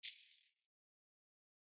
Thursday Chain Perc (Not Exact).wav